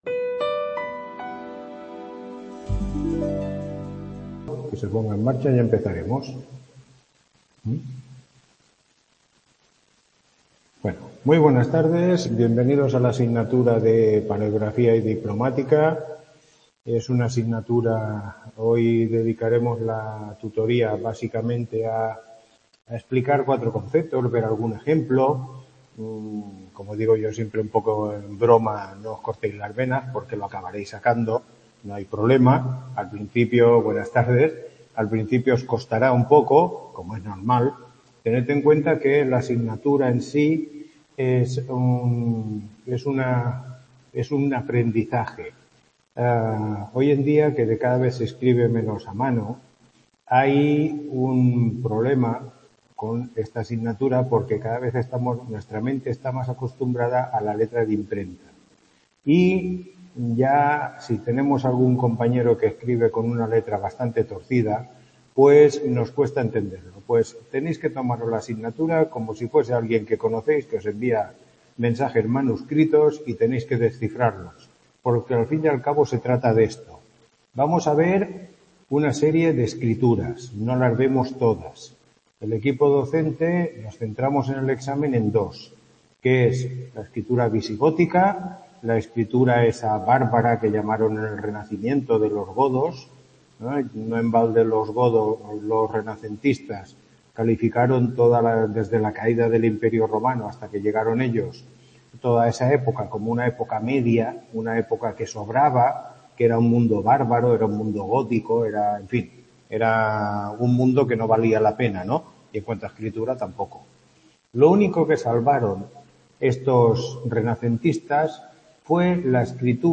Tutoría 01